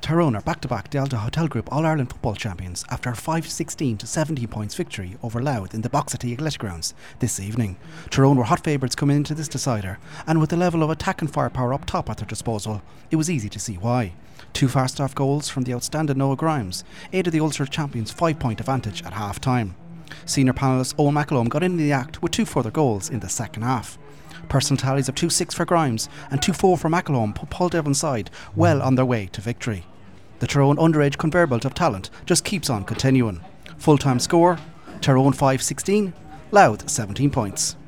full time report from Armagh